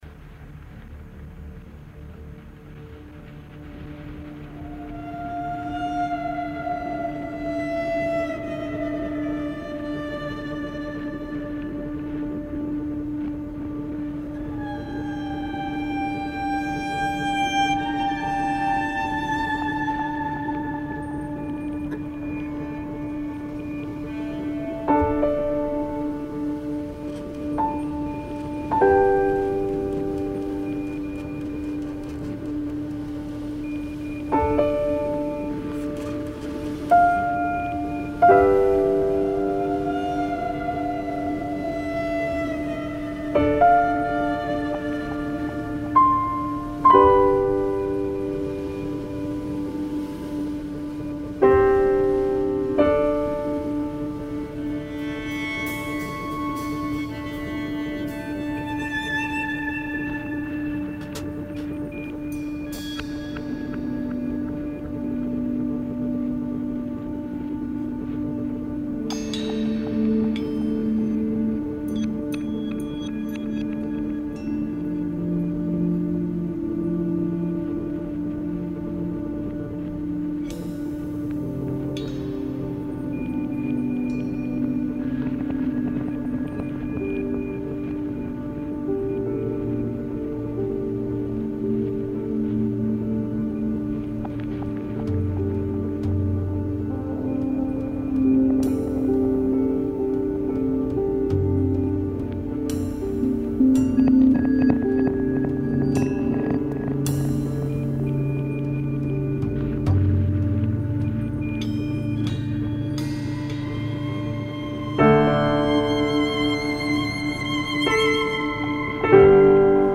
Ambient / Indie / Pop.